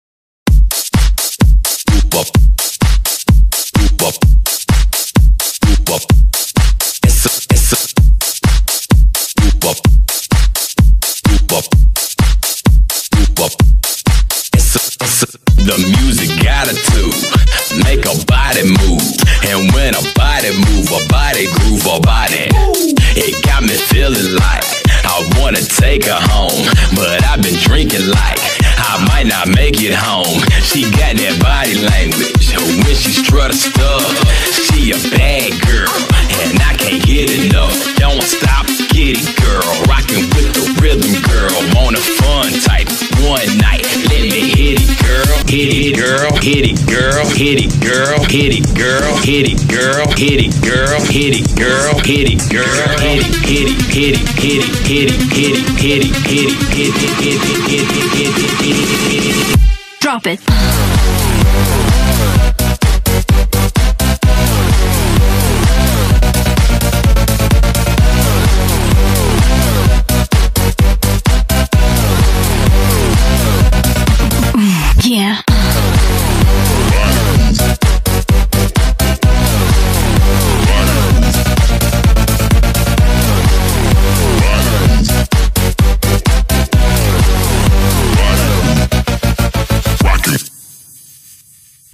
Audio QualityMusic Cut